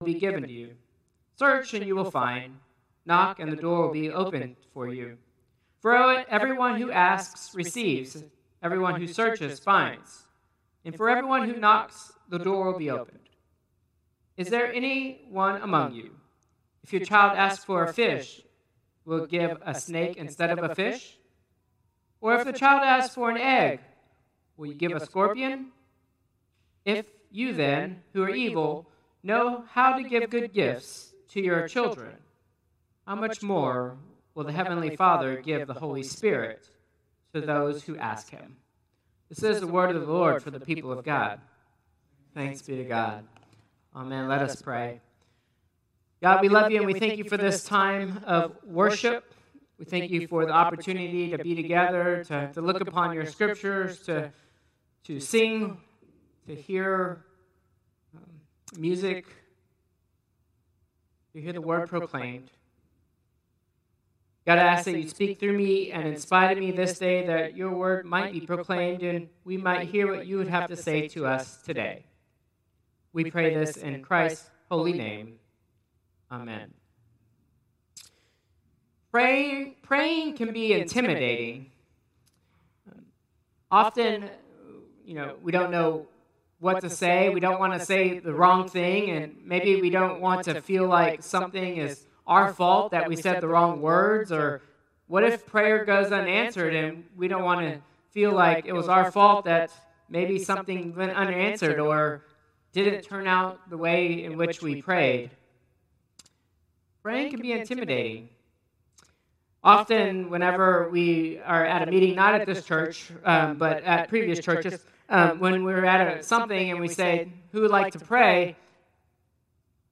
Traditional Service 7/27/2025